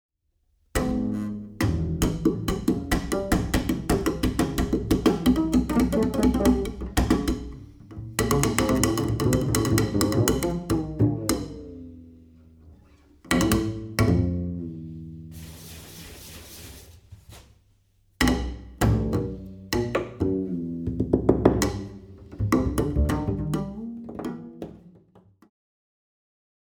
finger and bow with solo bass work.